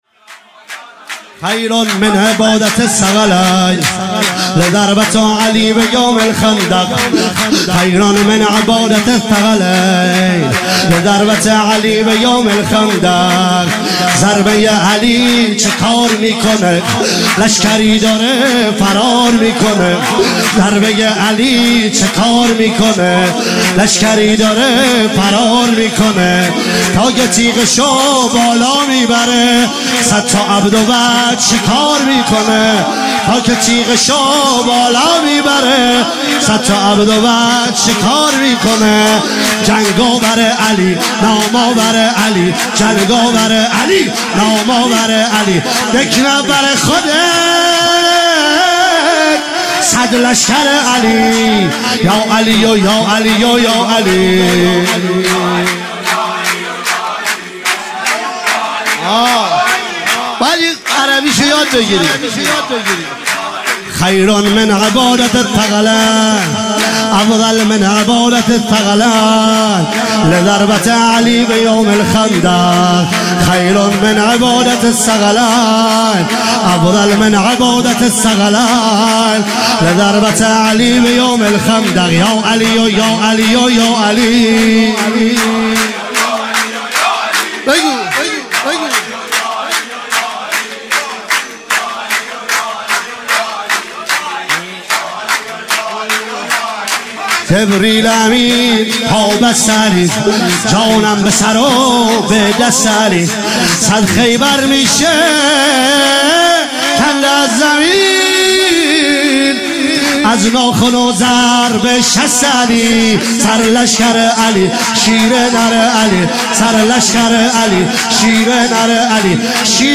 ولادت امام باقر علیه السلام97 - سرود - خیر من عباده الثقلین